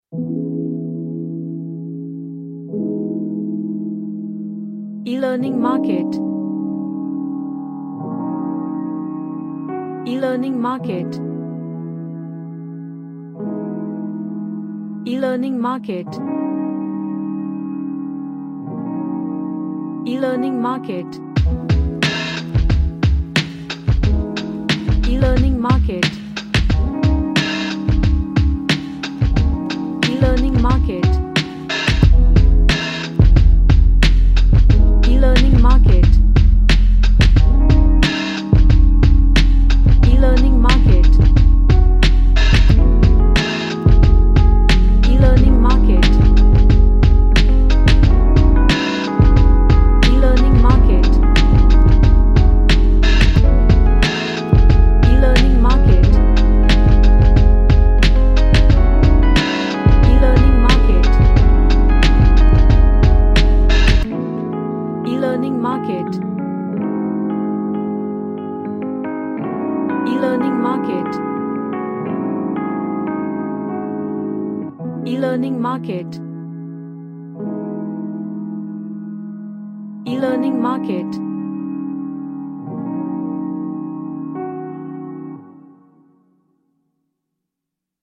A chill track with lot of distorted synths
Chill Out